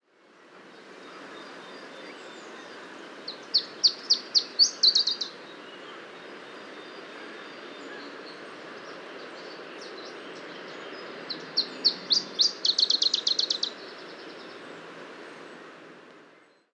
Unfortunately a heavy storm came up and we had only ten minutes for recording it.
PFR10758, 1-00, 150505, Iberian Chiffchaff Phylloscopus ibericus, song
Snakkerburen, Netherlands, Telinga prabolic reflector